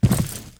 FallImpact_Concrete 03.wav